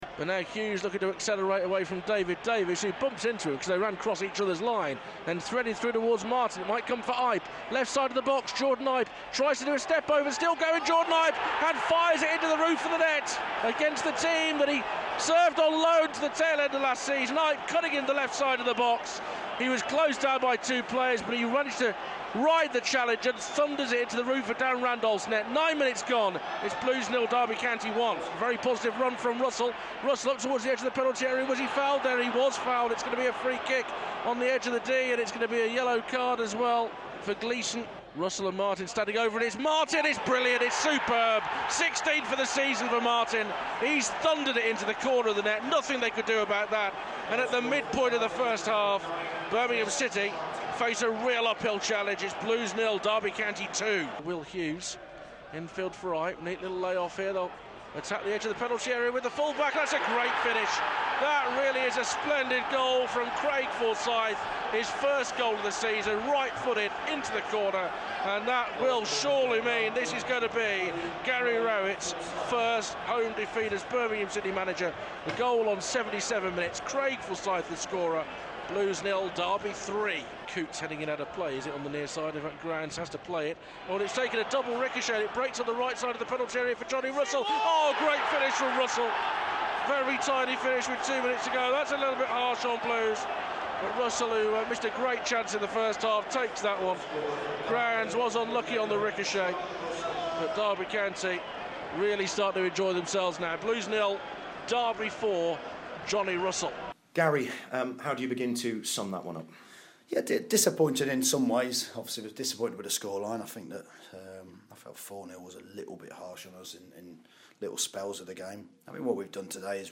describes the action and Gary Rowett gives his reaction.